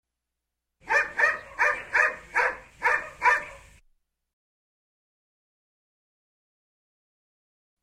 В зоопарке: шимпанзе, зебры, слоны, носорог, медведь Скачать звук music_note Животные , звери save_as 1 Мб schedule 1:09:00 9 3 Теги: mp3 , Дикие животные , животные , звук , звуки животных , зебра , медведь , носорог , слон